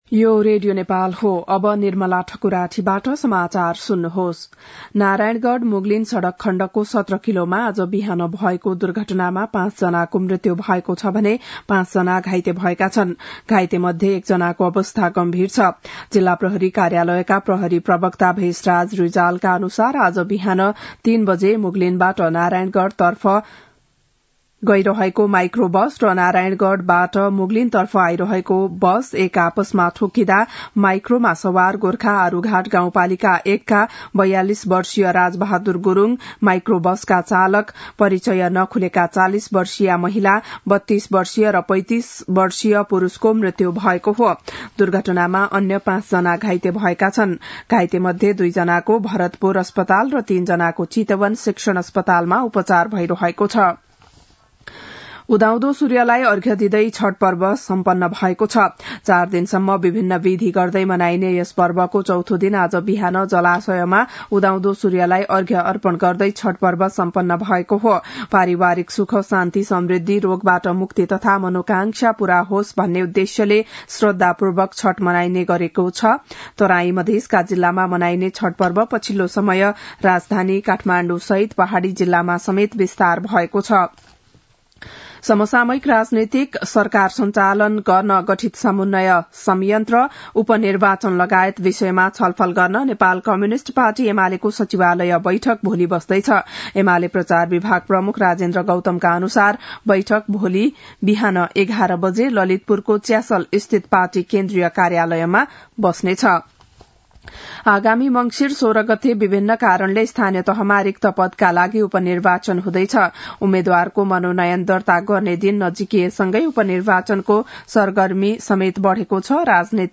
बिहान ११ बजेको नेपाली समाचार : २४ कार्तिक , २०८१